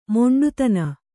♪ moṇḍutana